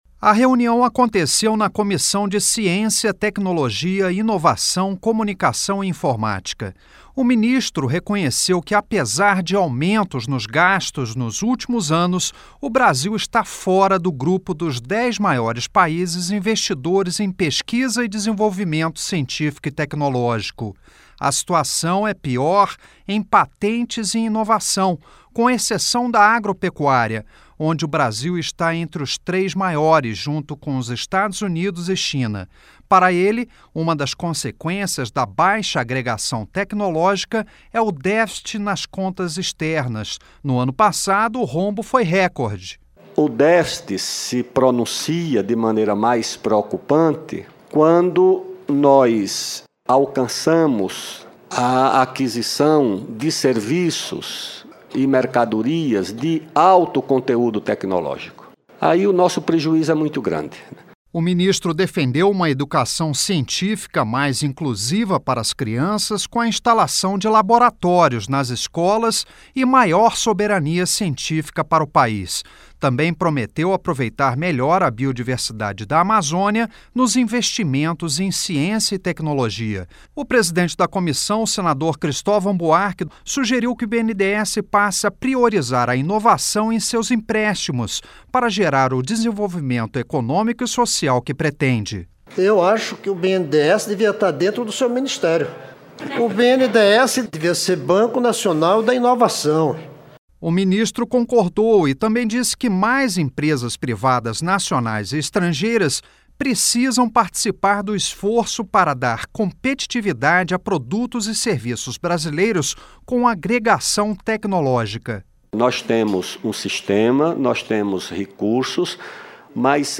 LOC: A AVALIAÇÃO É DO MINISTRO DA CIÊNCIA, TECNOLOGIA E INOVAÇÃO, ALDO REBELO, QUE PARTICIPOU PELA MANHÃ DE UMA AUDIÊNCIA PÚBLICA NO SENADO.